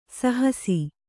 ♪ sahasi